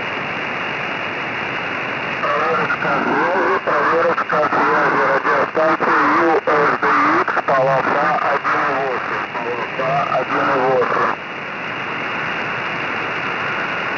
На остальных значениях полосы всё в порядке, есть небольшой подхрип, типичный для uSDX, некритичный для работы.
Вот примеры с WEBSdr:
Полоса 1800Гц